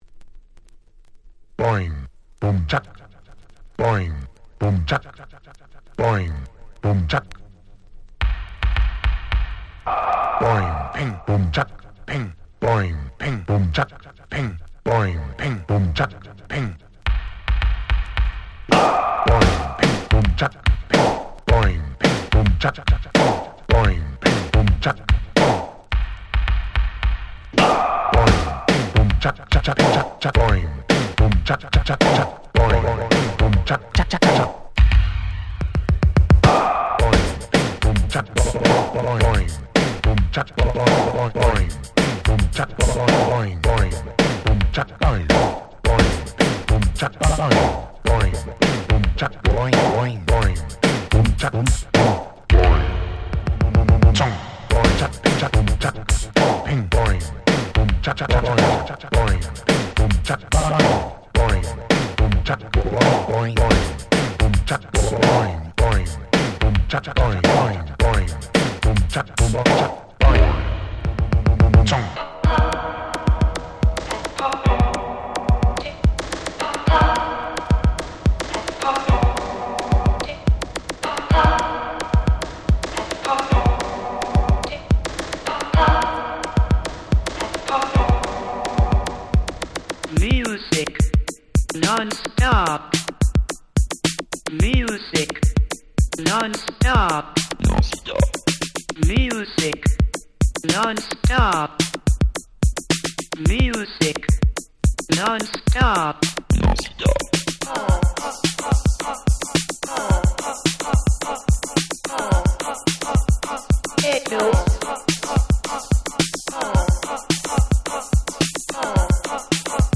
86' 世界的ヒットエレクトロ！！
Electro 80's